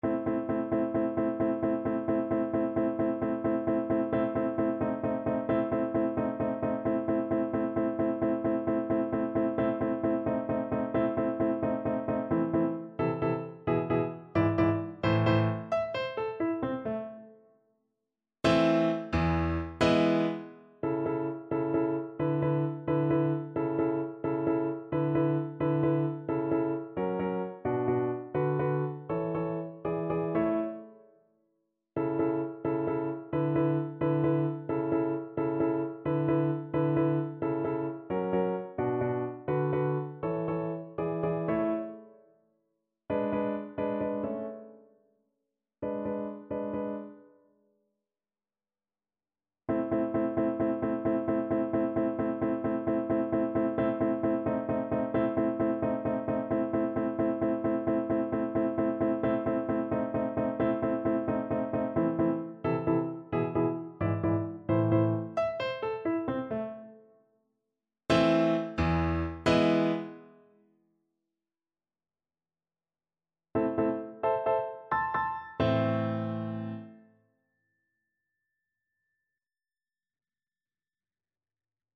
Viola
A minor (Sounding Pitch) (View more A minor Music for Viola )
Allegro con brio (.=104) .=88 (View more music marked Allegro)
Classical (View more Classical Viola Music)